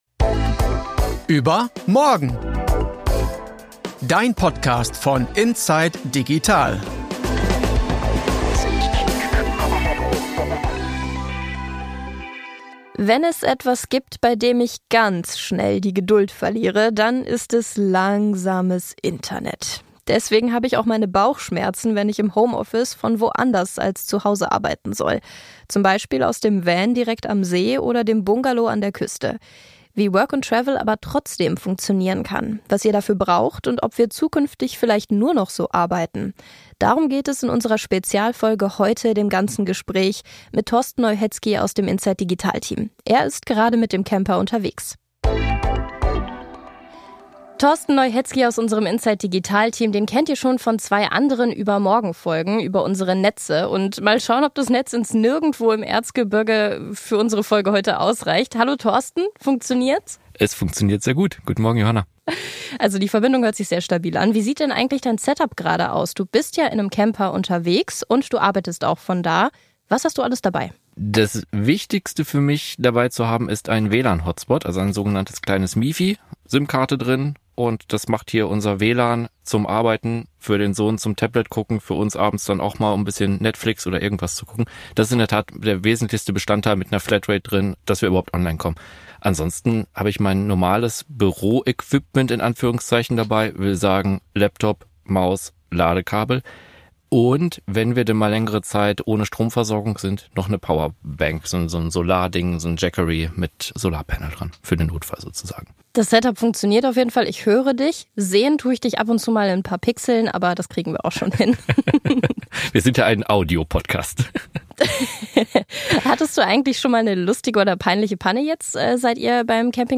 Arbeiten von überall: Das ganze Gespräch über Camping und Workation ~ überMORGEN – dein Podcast von inside digital Podcast